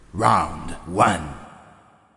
一组7个句子，可用作战斗画外音。
标签： 语音 战斗 战斗 木根 裁判 配音
声道立体声